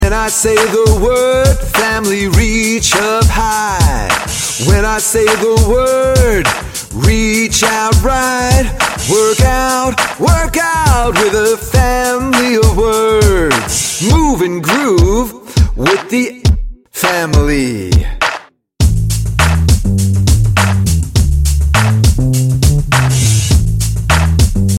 A movement song teaching word families!